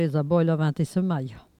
Langue Maraîchin
Locution